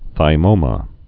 (thī-mōmə)